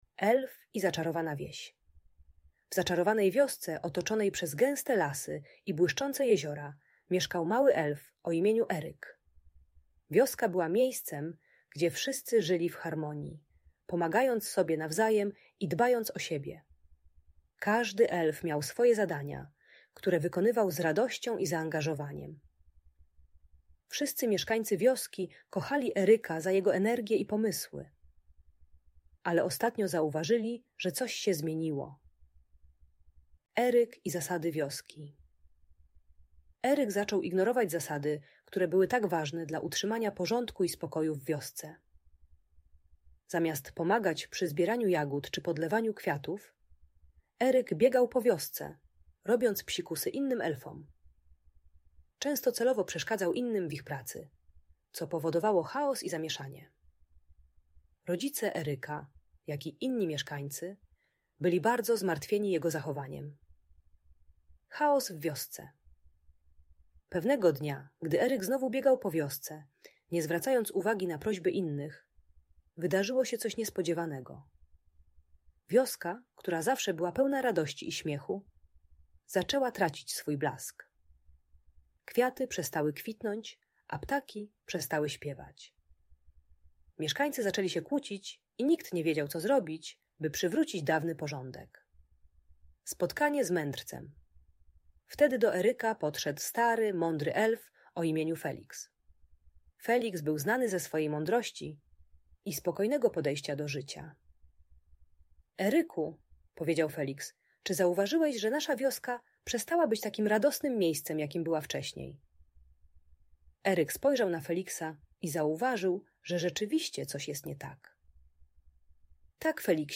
Elf i Zaczarowana Wieś - Urocza historia pełna przygód - Audiobajka